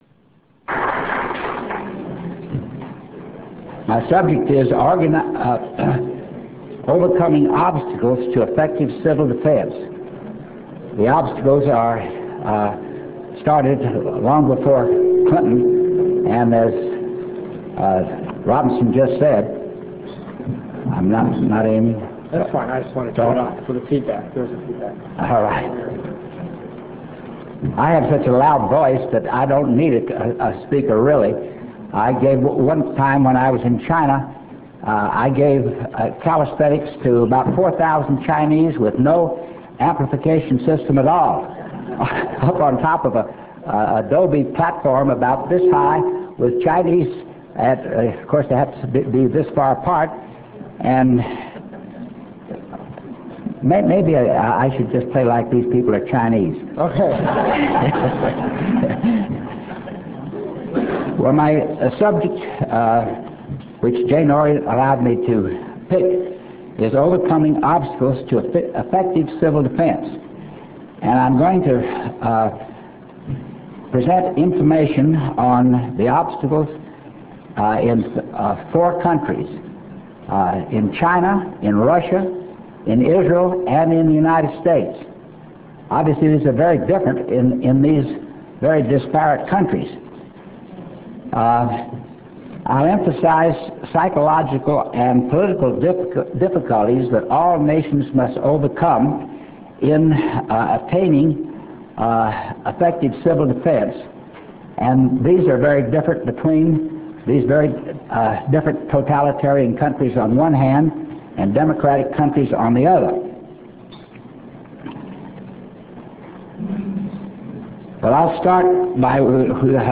Free Real Audio Speeches.